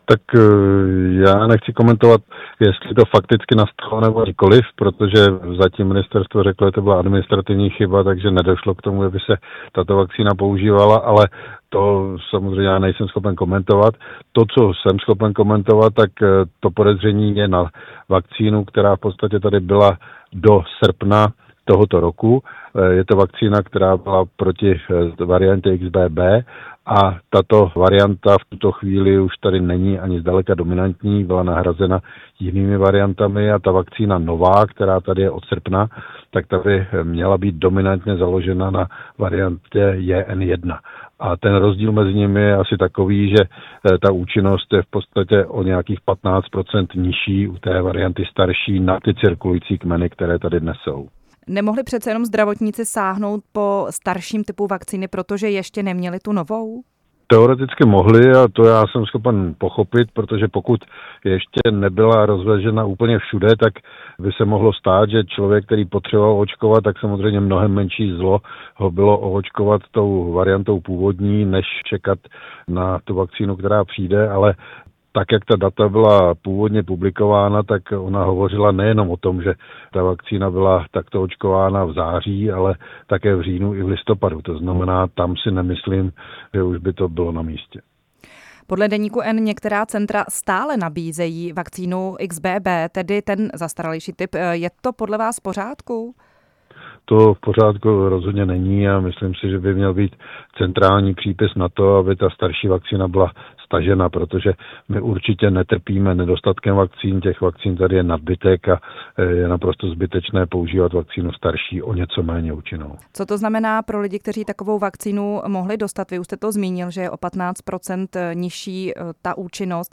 Roman Prymula hostem vysílání Radia Prostor